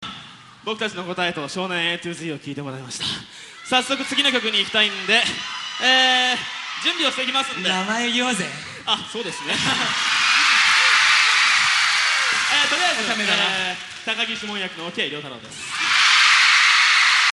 Certainly he plays lead guitar.